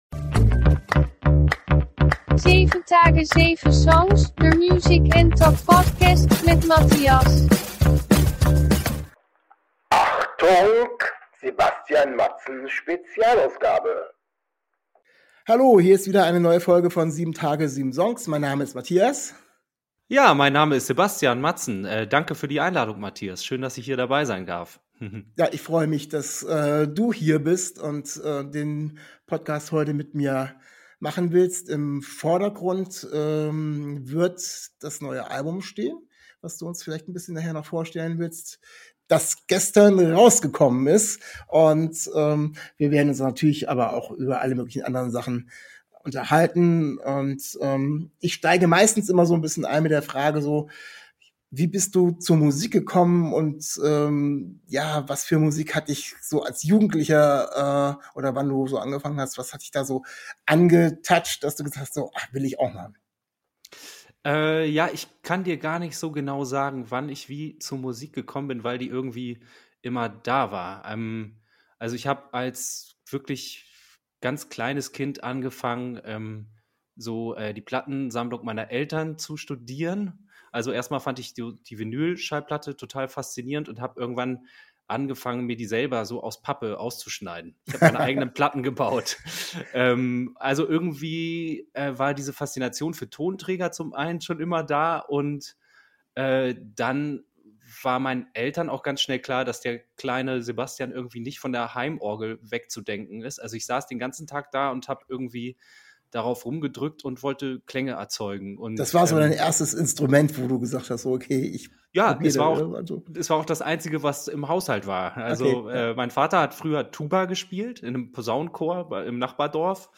Oktober 2022 Nächste Episode download Beschreibung Kapitel Teilen Abonnieren Zu Gast ist Sebastian Madsen, Sänger der Band MADSEN. Er stellt sein Soloalbum EIN BISSCHEN SEELE vor, erzählt wie es zu dem Projekt kam und wir plaudern über seinen musikalischen Werdegang.